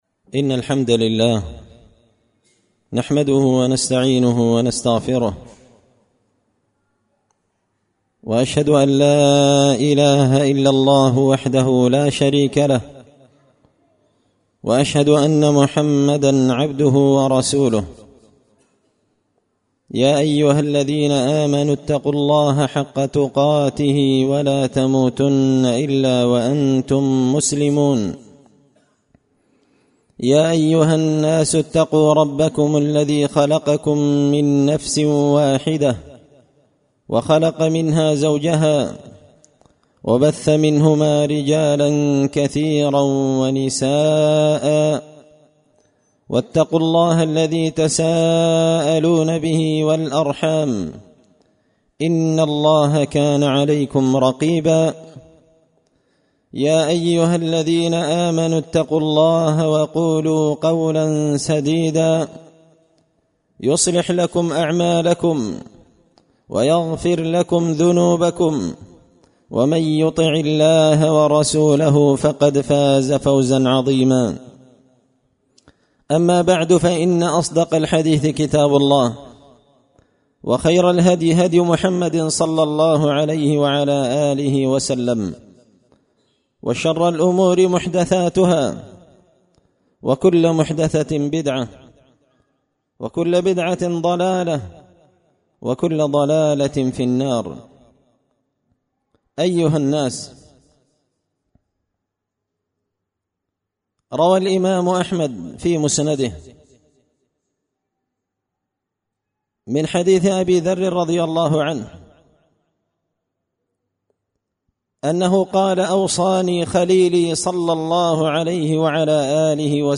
خطبة جمعة بعنوان – أمرني خليلي بسبع
دار الحديث بمسجد الفرقان ـ قشن ـ المهرة ـ اليمن